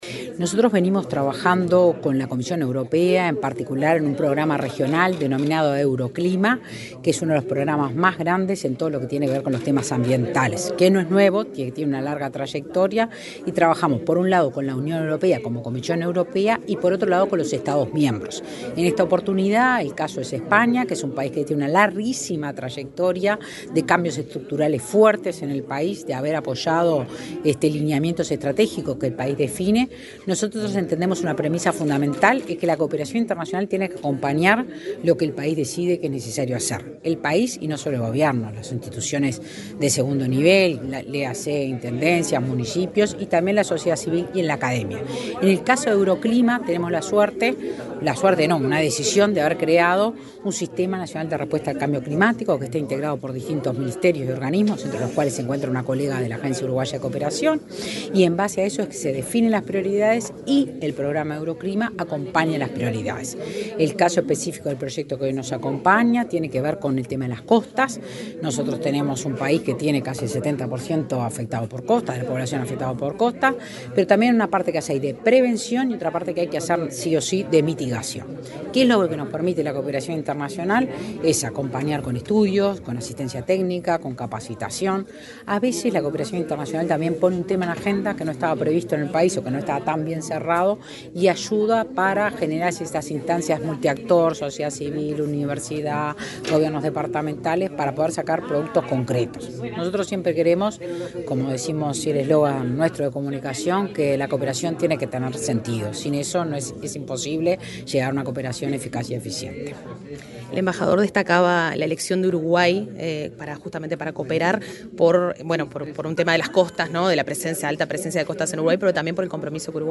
Entrevista a la gerenta de AUCI, Claudia Romano
La gerenta de la Agencia Uruguaya de Cooperación Internacional (AUCI), Claudia Romano, dialogó con Comunicación Presidencial en Torre Ejecutiva, antes